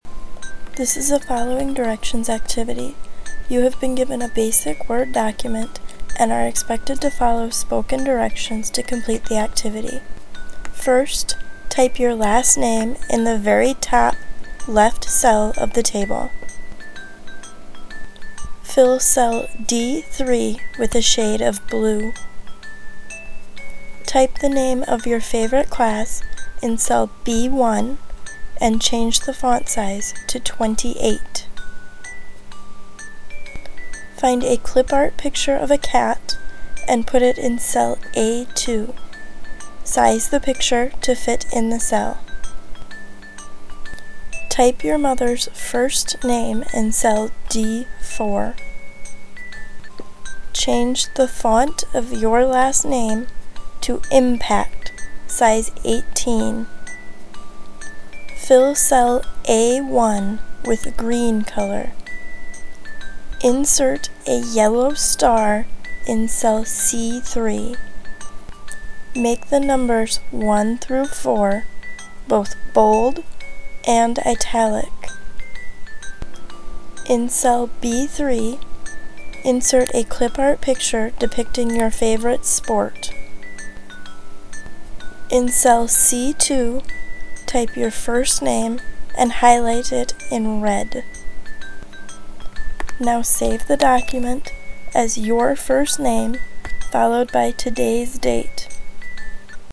Audacity This is a following directions activity.  Students will be given a  basic Word document and will be expected to follow spoken directions to complete the activity.